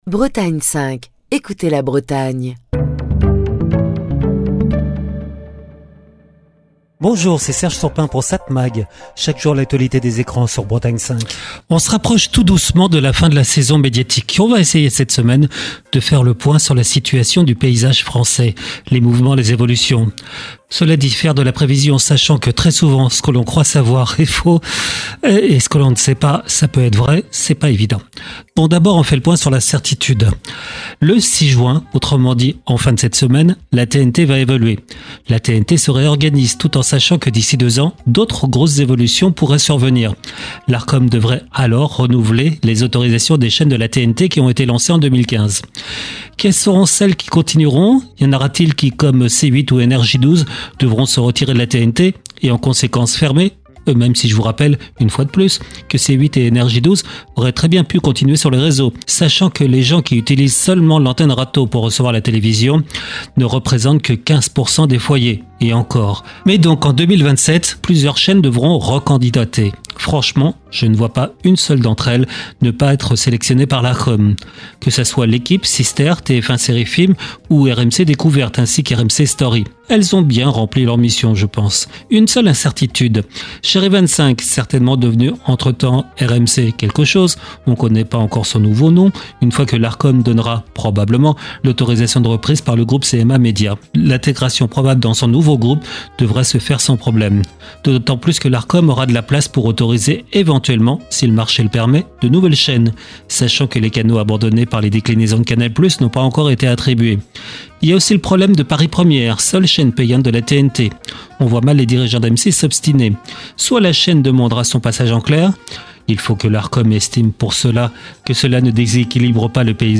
Chronique du 2 juin 2025.